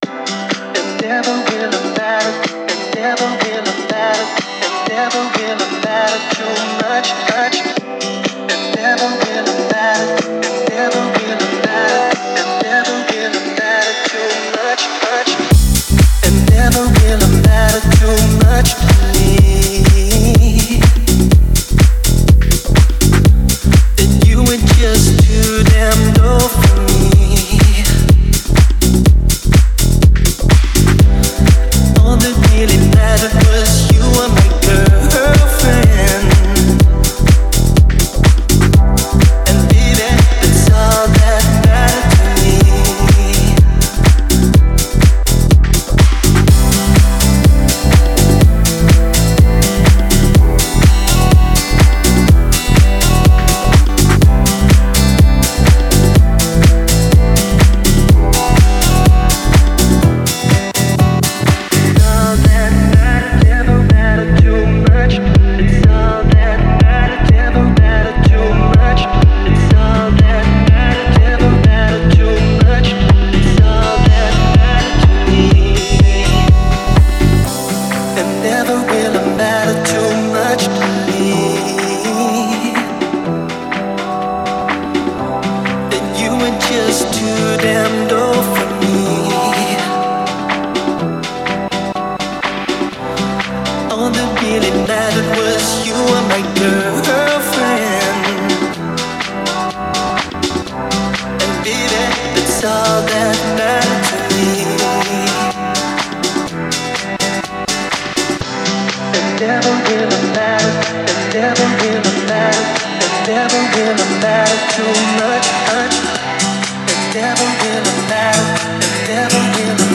это энергичная танцевальная композиция в жанре house